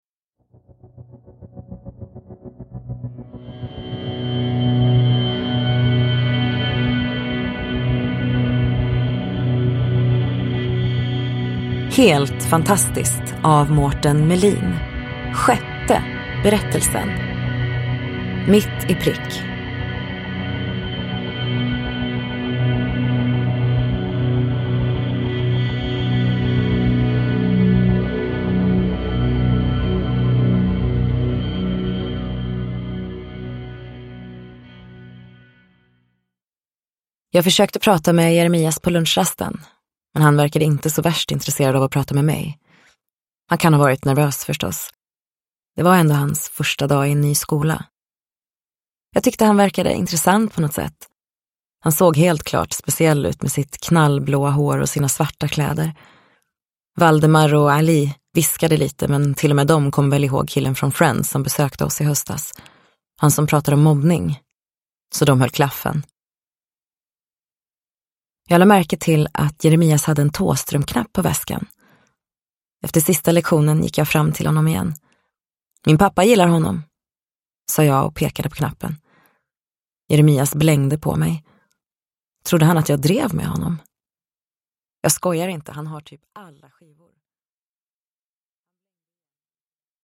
Mitt i prick : en novell ur samlingen Helt fantastiskt – Ljudbok